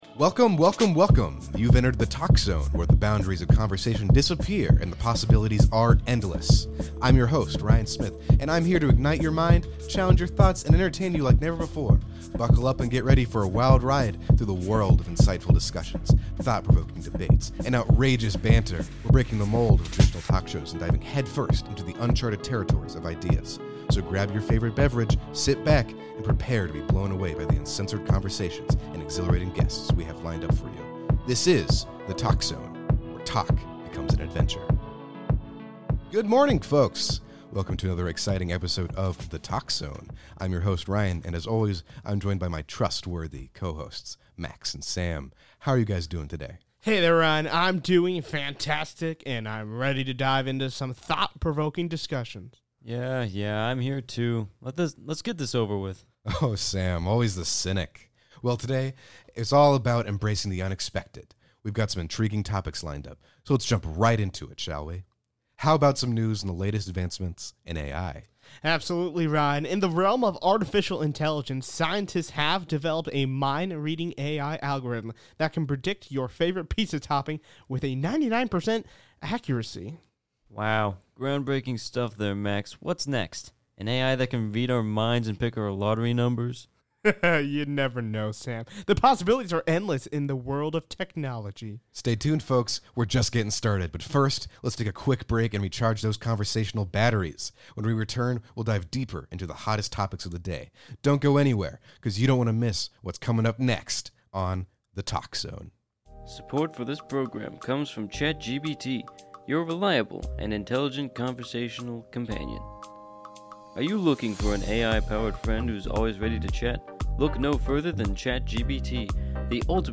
In this intriguing talk show